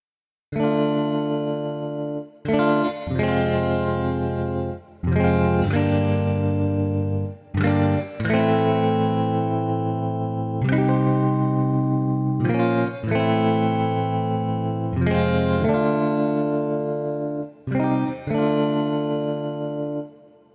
Zwei Amps (wahrscheinlich british und american) sowie ein Federhall bilden die Grundlagen der Klangbildung.
desloate-guitars-3.mp3